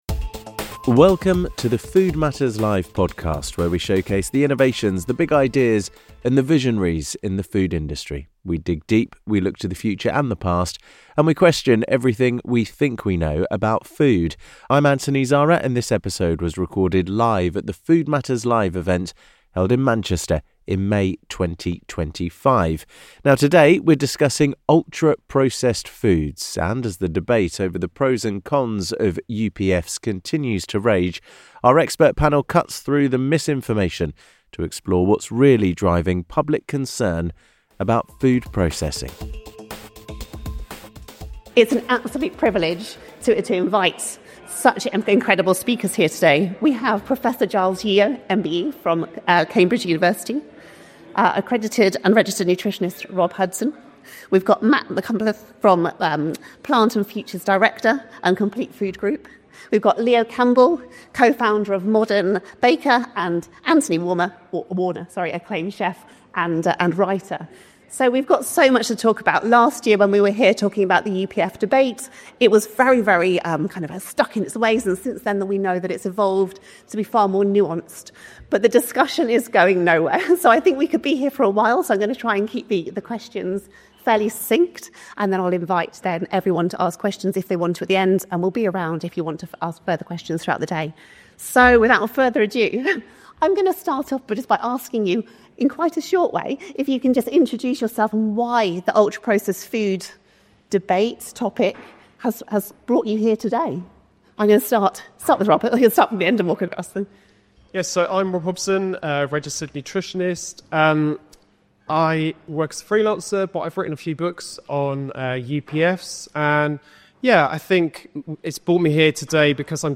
In this episode of the Food Matters Live podcast, recorded at our event in Manchester in May 2025, our expert panel cuts through the misinformation to explore what's really driving public concern about food processing.